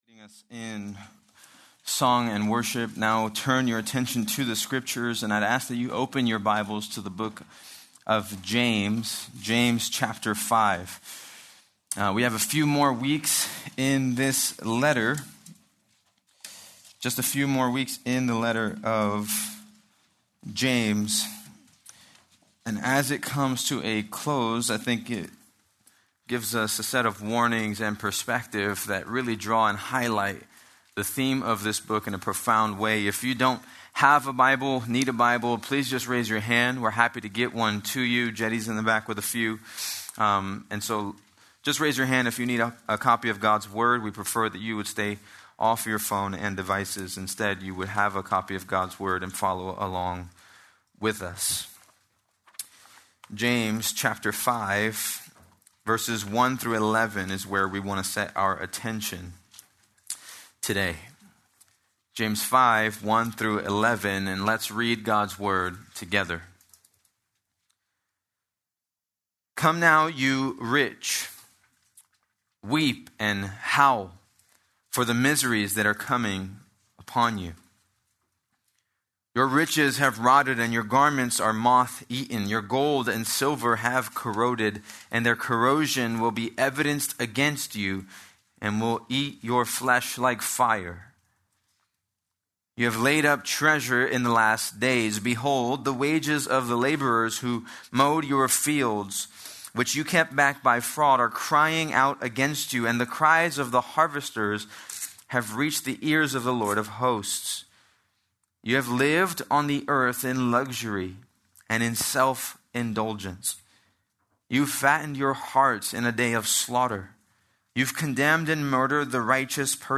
Due to technical difficulties, this sermon is incomplete.